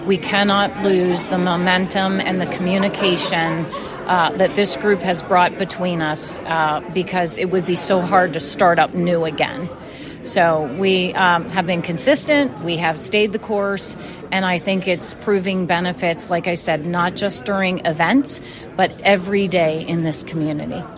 Tuesday’s meeting also marked the last one for Indiana County Commissioner Robin Gorman, who has served as I-ACT Co-Chair for several years. She said the group has been beneficial to the community, but it needs to keep growing.